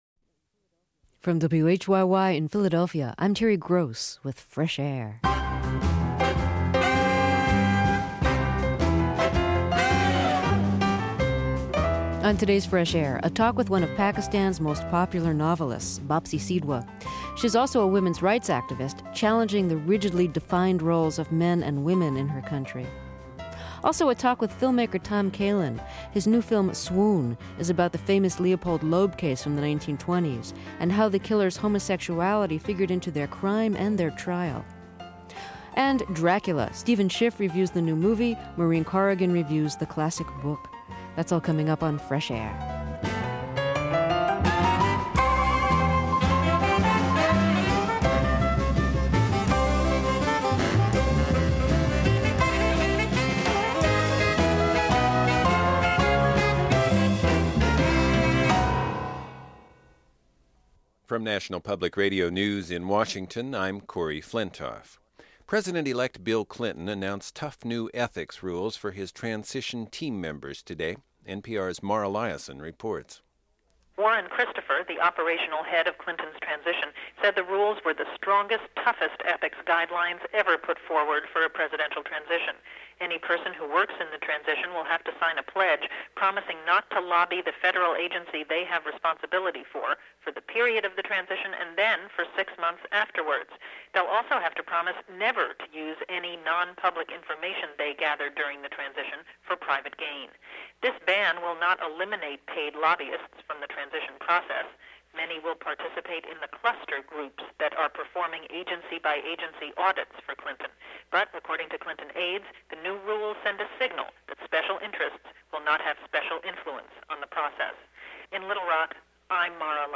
Dracula | Fresh Air Archive: Interviews with Terry Gross